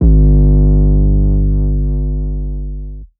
TS 808 5.wav